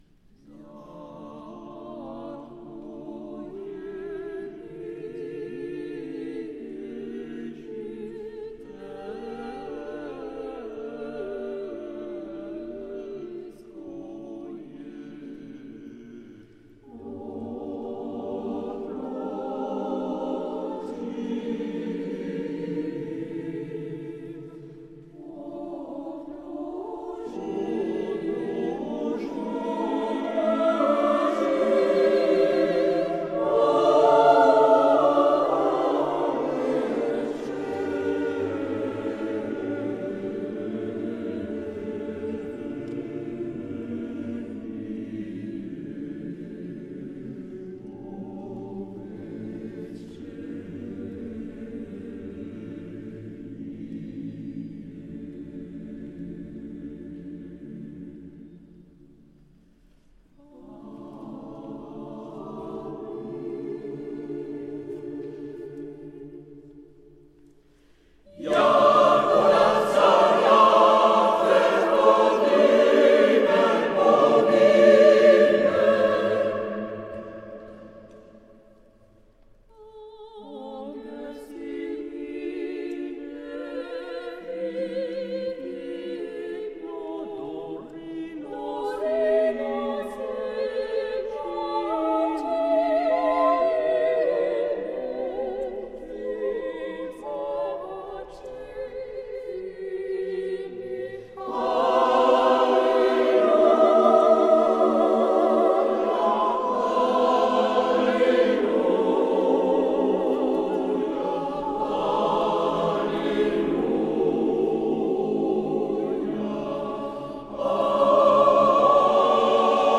Ce choeur est omposé d'une trentaine de chanteurs et solistes, spécialistes de ce répertoire.
Concert de chants sacrés du choeur orthodoxe